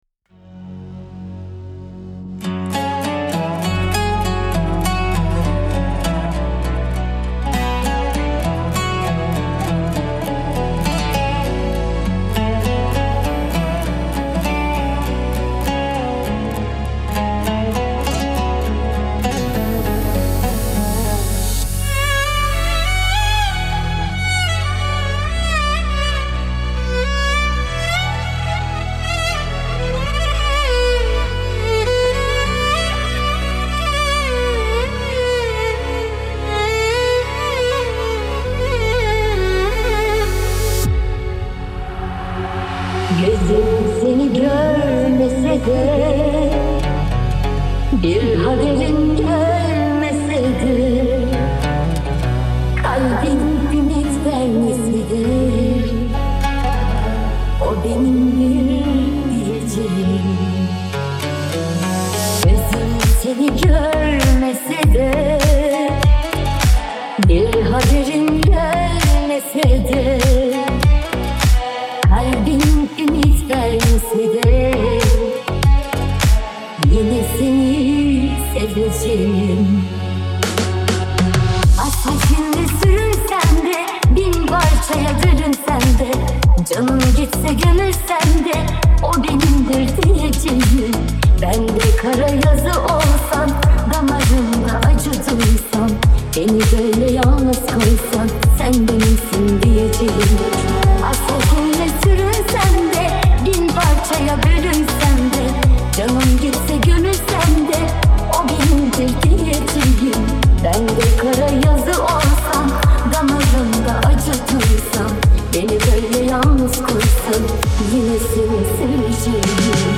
Трек размещён в разделе Турецкая музыка / Танцевальная.